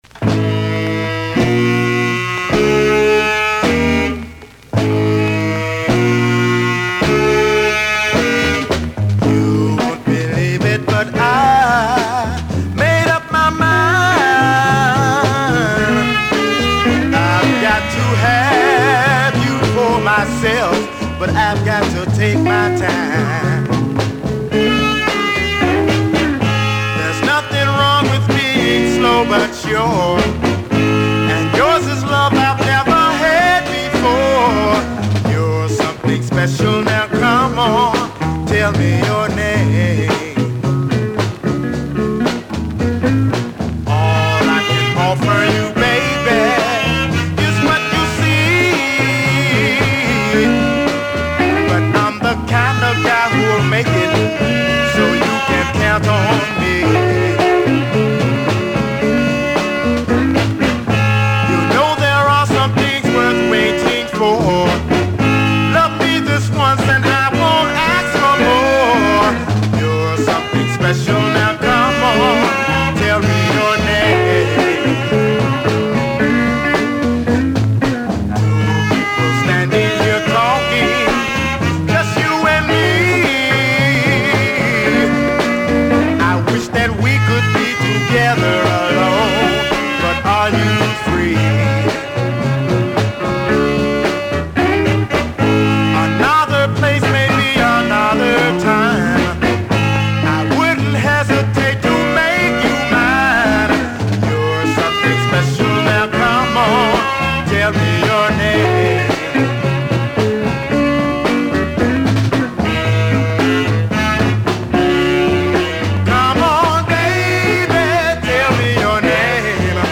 Legendary Soul two-sider from Lynchburg, VA.